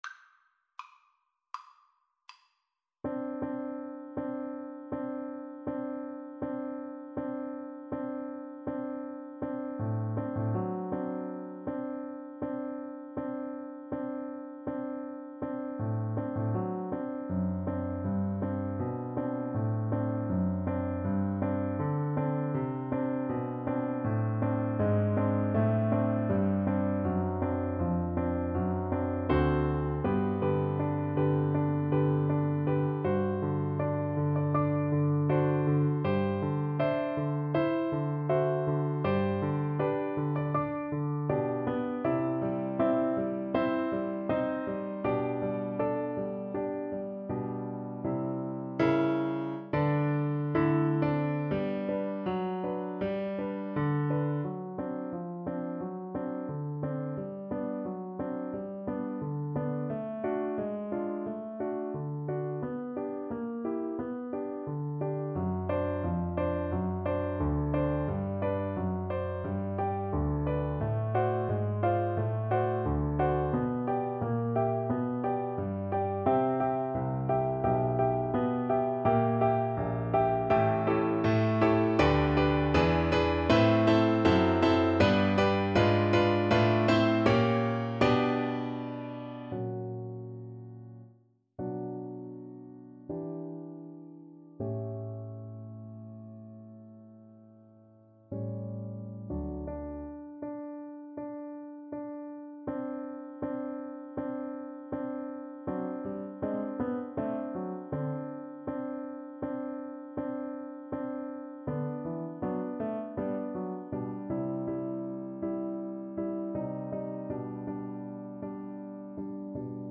Play (or use space bar on your keyboard) Pause Music Playalong - Piano Accompaniment Playalong Band Accompaniment not yet available reset tempo print settings full screen
G major (Sounding Pitch) (View more G major Music for Flute )
Andante espressivo
Classical (View more Classical Flute Music)